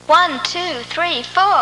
Intro Sound Effect
Download a high-quality intro sound effect.